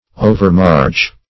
Search Result for " overmarch" : The Collaborative International Dictionary of English v.0.48: Overmarch \O`ver*march"\, v. t. & i. To march too far, or too much; to exhaust by marching.